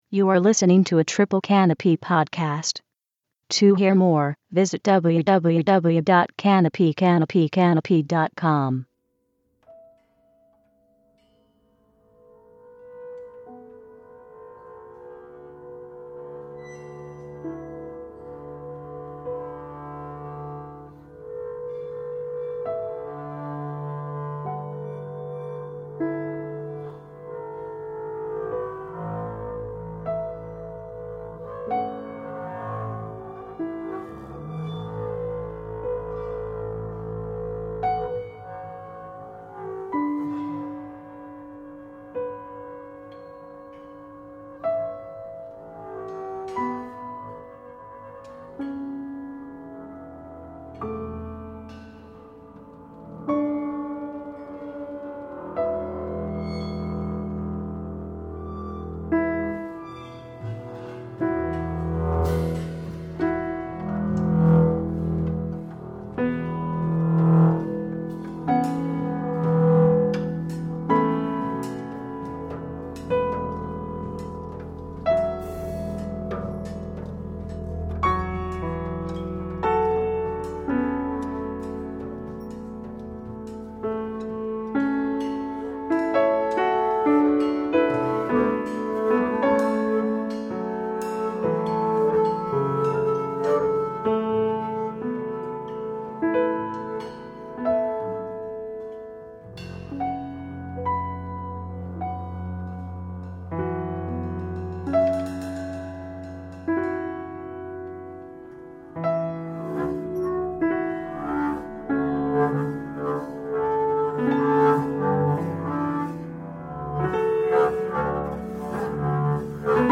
percussion
contrabass
This podcast contains three live recordings.
both recorded at Roulette in New York City on September 24